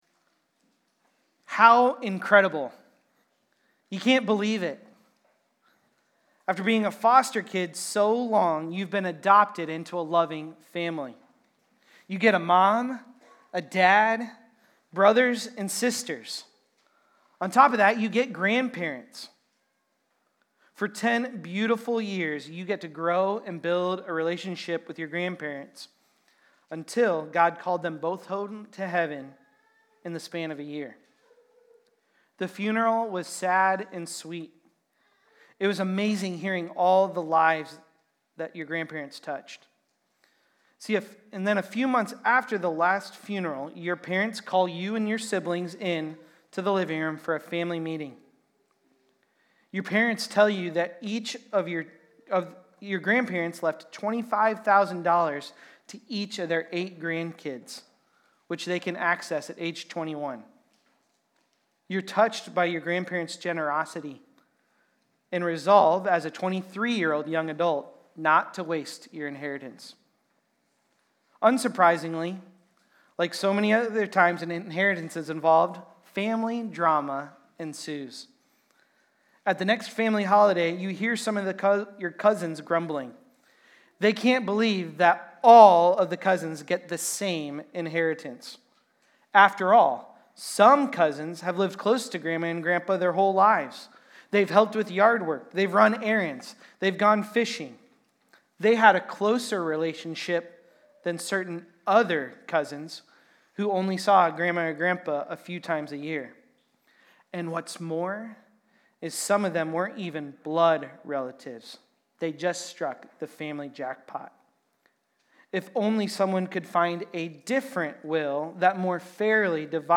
Galatians-3.15-22-Sermon-Audio.mp3